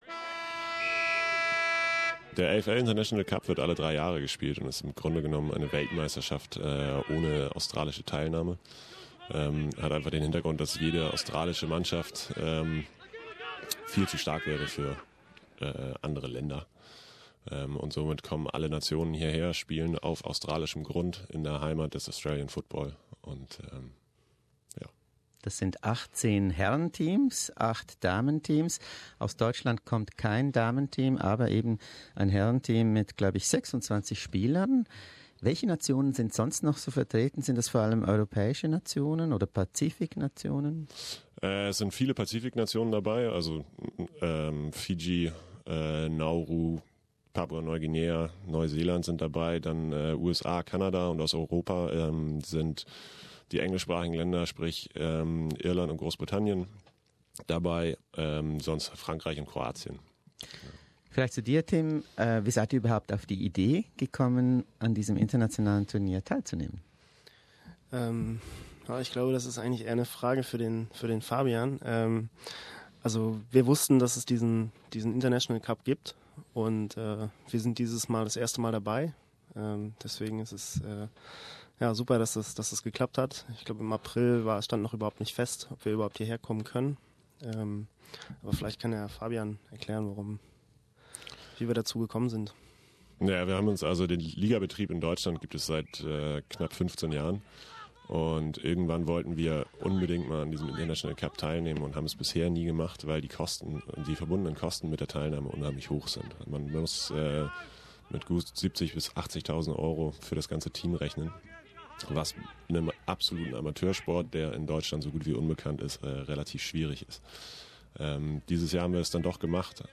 We invited two of the team's 26 passionate amateur players to our studio.